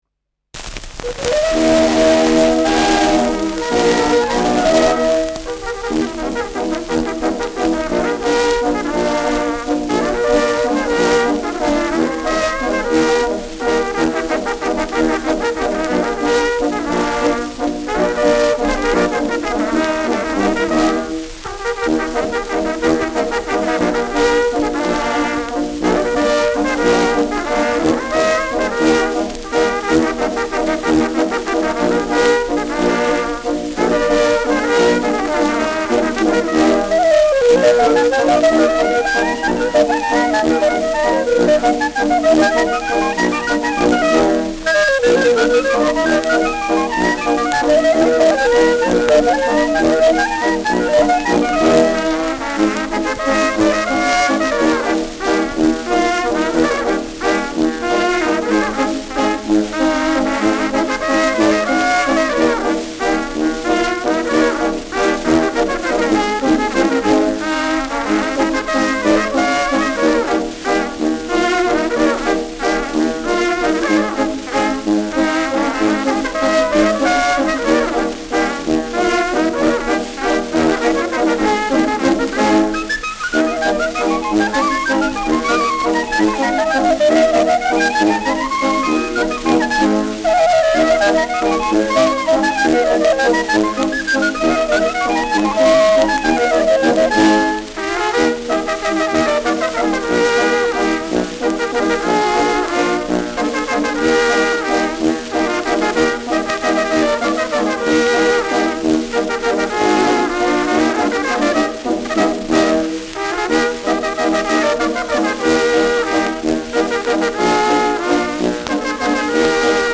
Schellackplatte
präsentes Rauschen : „Schnarren“ : präsentes Knistern : leiert : vereinzeltes Knacken